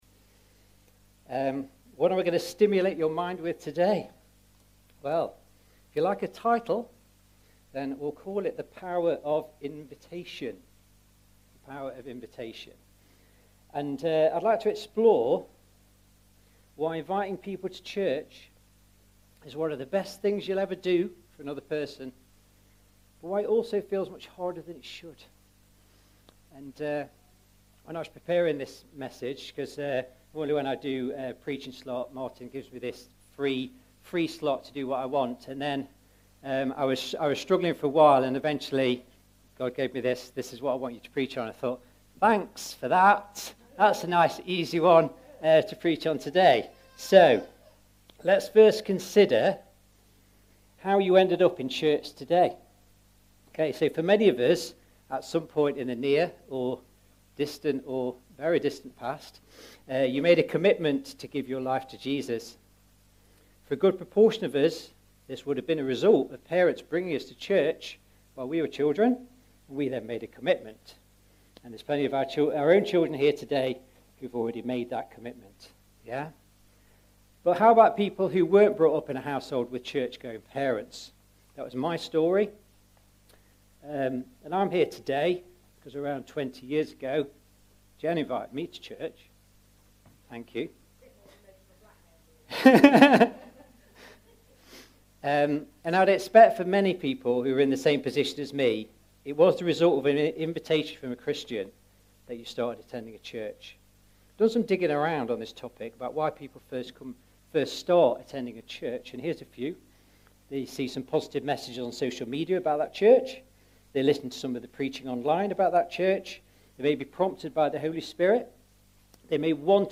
A message from the series "Sunday Morning." God reveals Himself as our provider, in this message we consider how Gods provision needs to be understood and worked out in our lives.